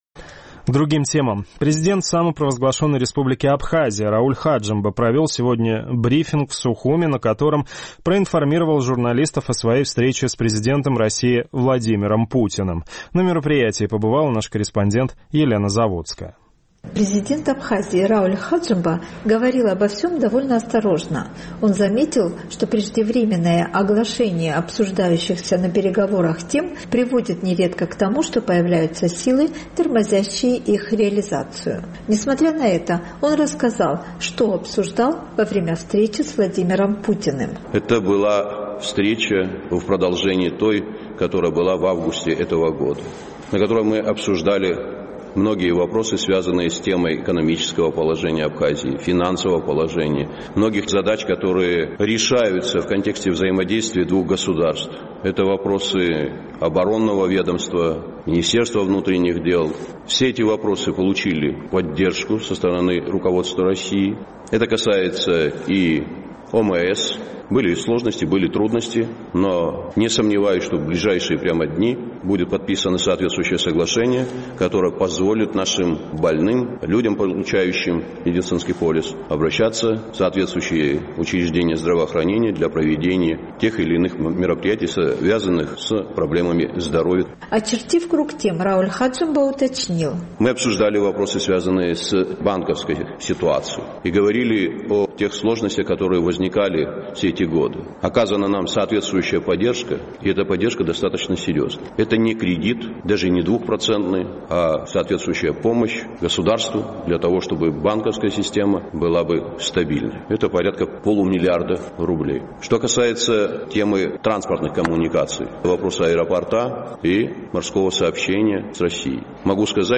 Президент Абхазии Рауль Хаджимба на брифинге в Сухуме проинформировал журналистов о своей встрече с президентом России Владимиром Путиным, рассказал о том, какие темы обсуждались, и немного их конкретизировал.